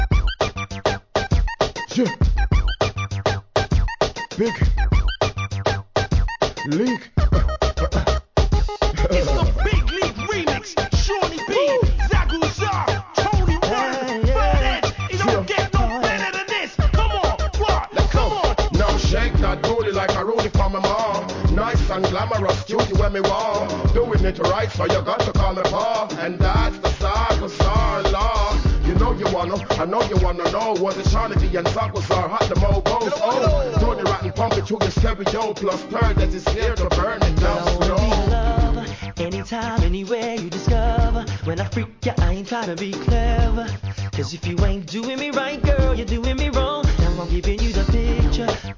UK R&B